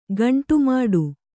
♪ gaṇṭu māḍu